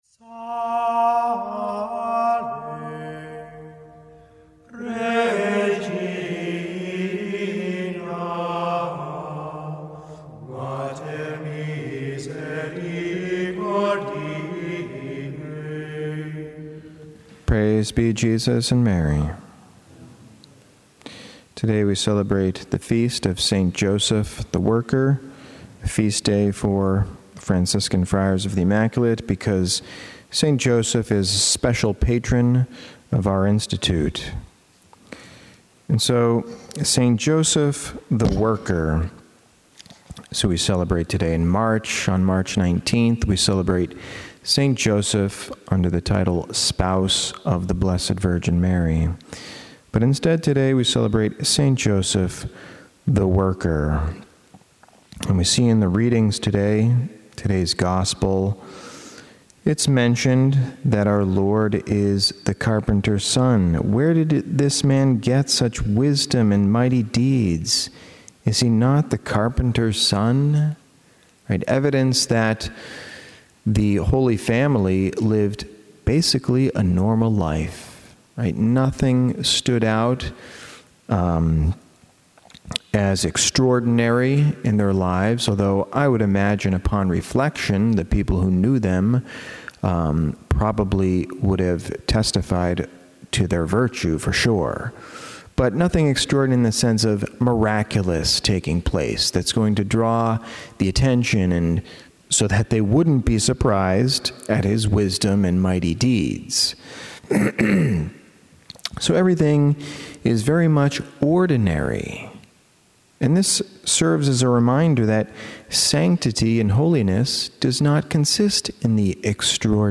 Homily
Mass: St. Joseph the Worker - Feast - Form: OF Readings: Friday 4th Week of Easter 1st: act 13:26-33 Resp: psa 2:6-7, 8-9, 10-11 Gsp: joh 14:1-6 Audio (MP3) +++